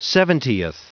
Prononciation du mot seventieth en anglais (fichier audio)
Prononciation du mot : seventieth